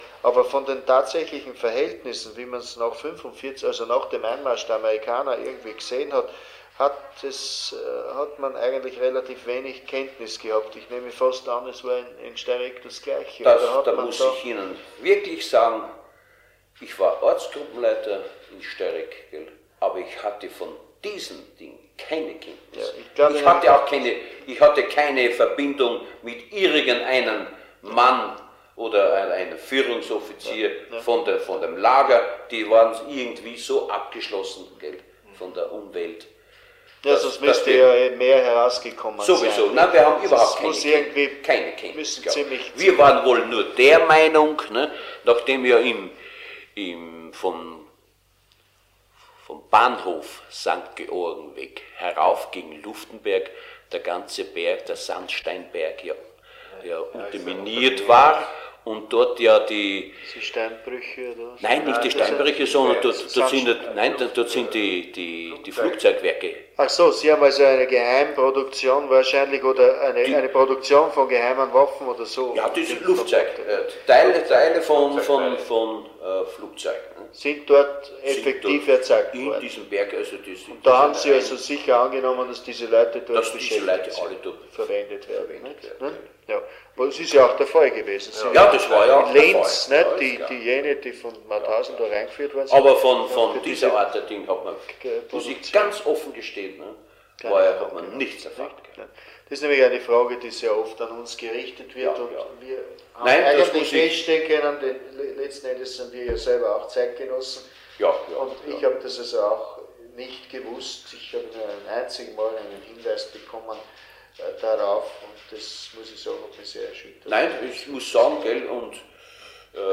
Ausschnitt aus einem Interview